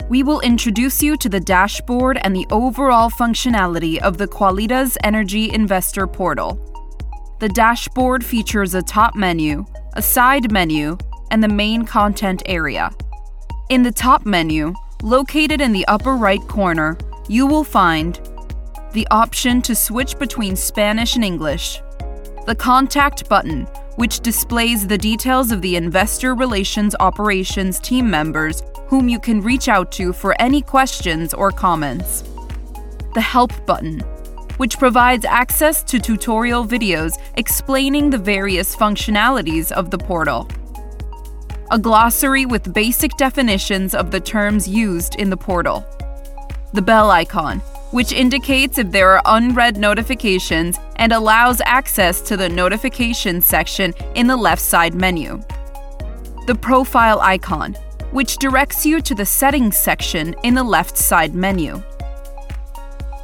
Apprentissage en ligne
Ma voix combine naturellement chaleur, clarté et énergie dynamique, ce qui en fait un choix parfait pour les projets qui nécessitent un son relatable et professionnel.